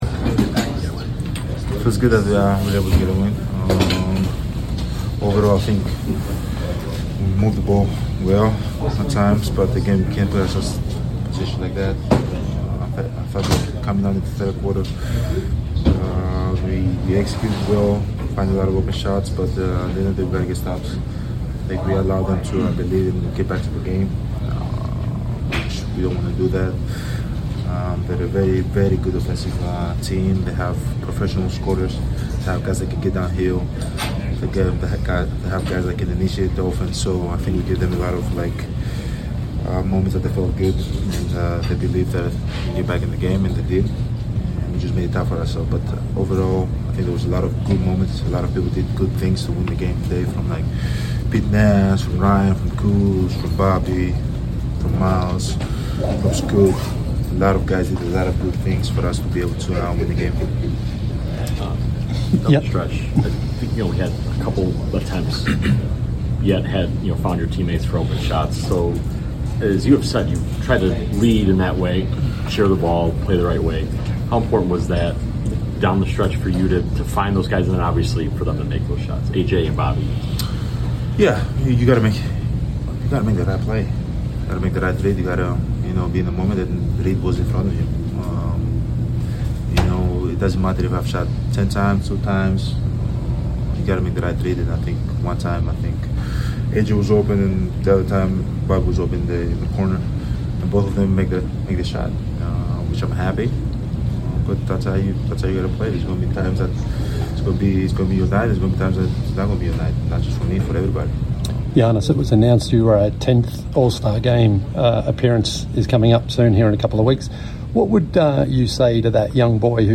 01-19-26 Milwaukee Bucks Forward Giannis Antetokounmpo Postgame Interview